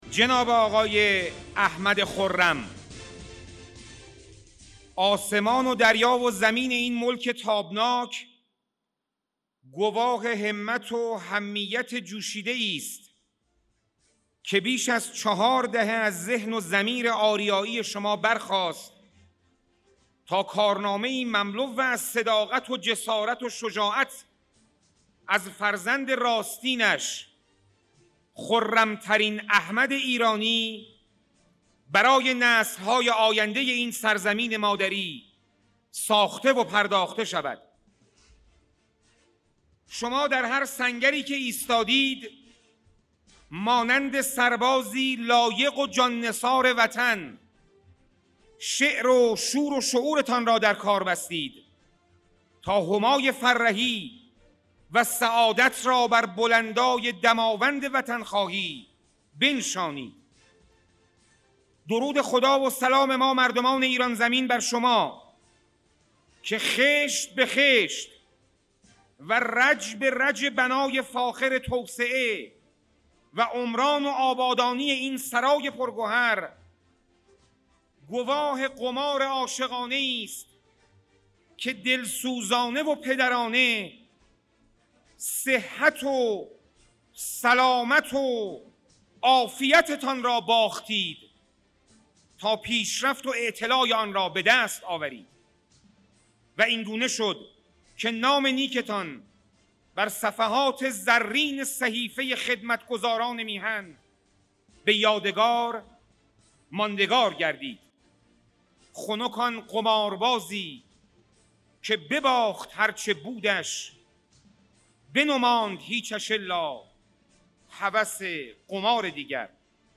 به گزارش تین نیوز، بر اساس اطلاعاتی که وب سایت سازمان نظام مهندسی ساختمان در مورد خرم منتشر کرده، سوابق تحصیلی و فعالیت شغلی و حرفه ای به شرح زیر است: ( برای دریافت فایل صوتی تجلیل از احمد خرم و صحبت‌های ارزشمند ایشان در دومین همایش حالِ خوبِ زندگی اصفهان- سالن همایش‌های سیتی‌سنتر-شهریور 1402 اینجا کلیک کنید)